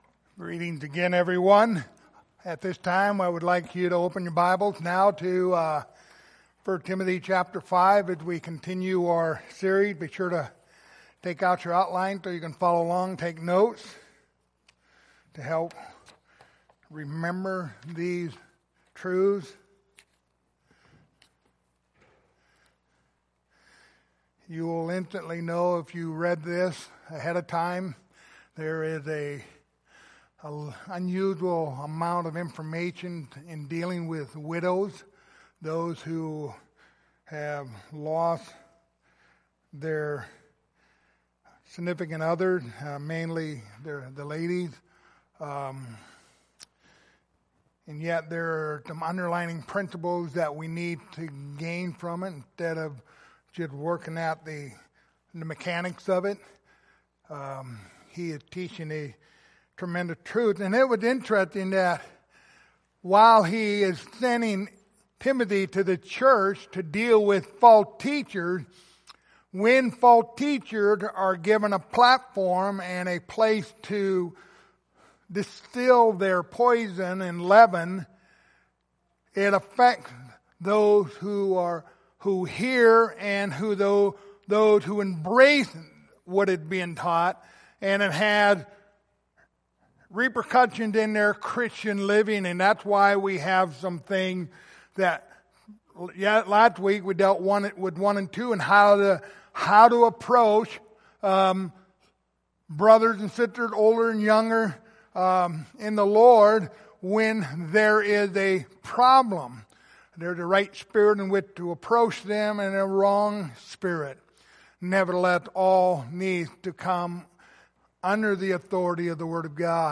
Passage: 1 Timothy 5:3-8 Service Type: Sunday Morning